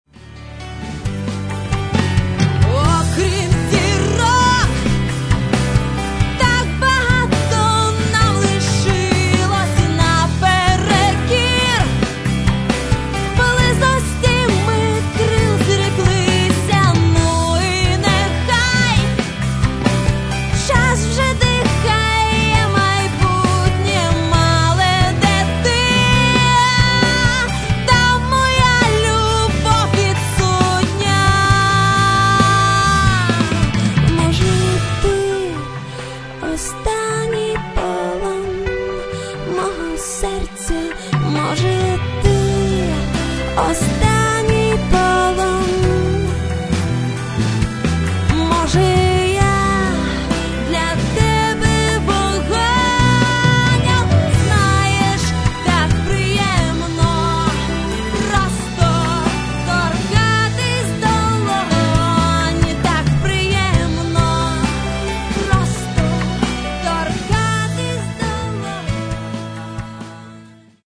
тут демка